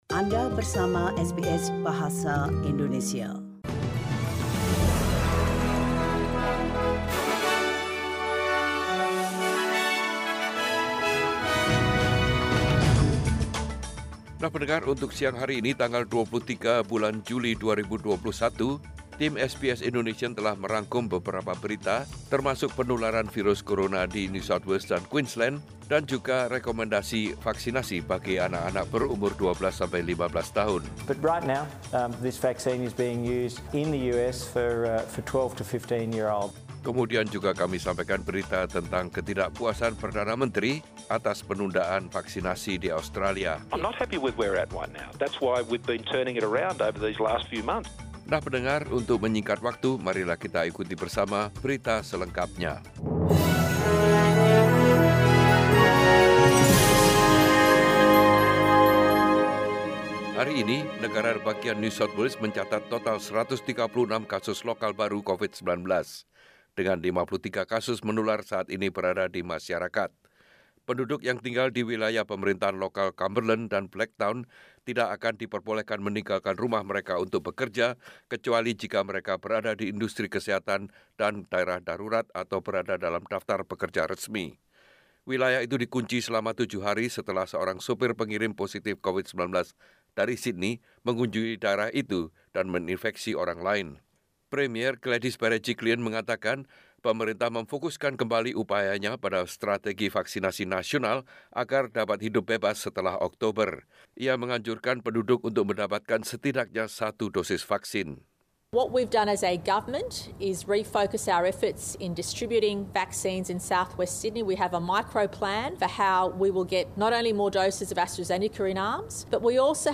SBS Radio News in Bahasa Indonesia - 23 July 2021
Warta Berita Radio SBS dalam Bahasa Indonesia Source: SBS